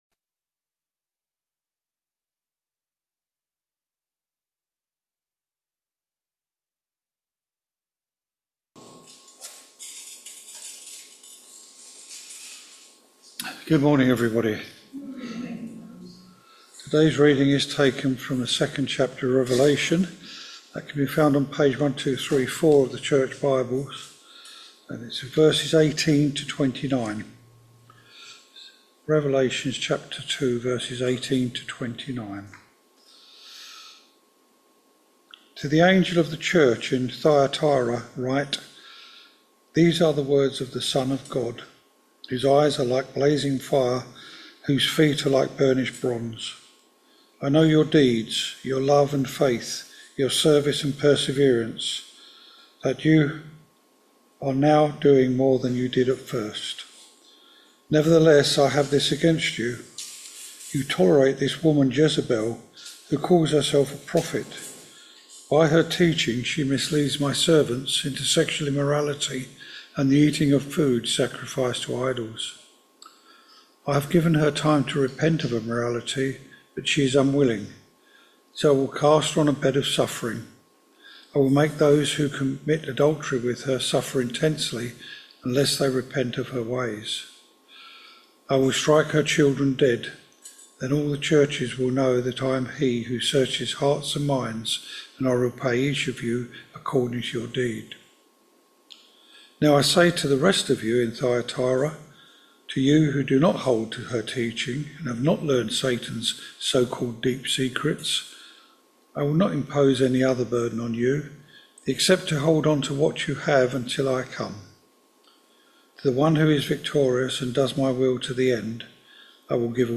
Revelation 2v18-29 Service Type: Sunday Morning Service Topics